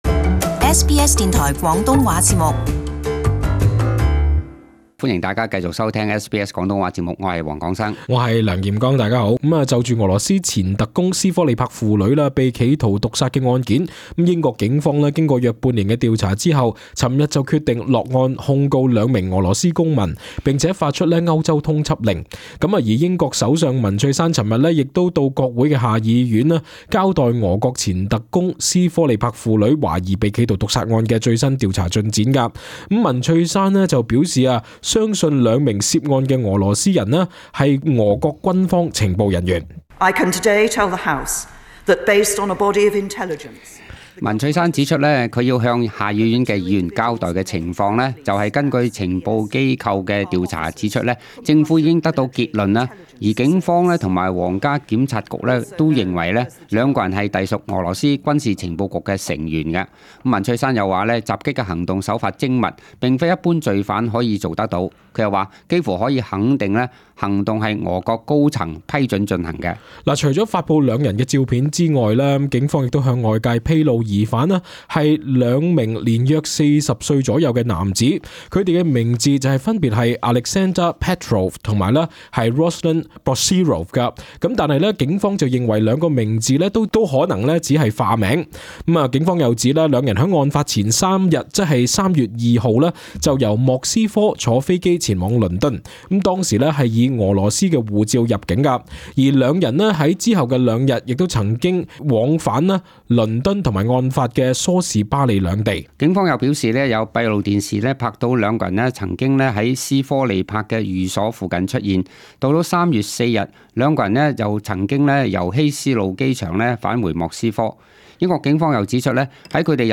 【時事報導】英國就斯科里帕父女中毒案起訴兩俄羅斯公民